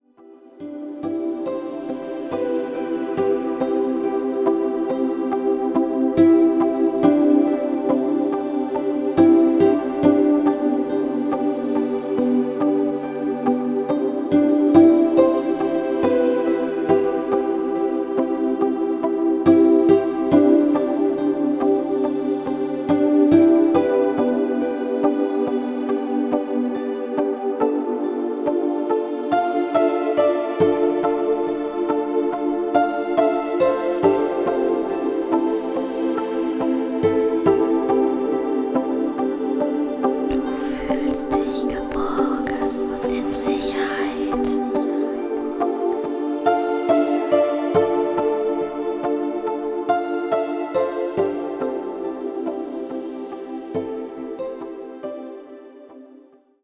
Entspannungsmusik mit Suggestionen – 8:25
Genre: Musik (Kinder)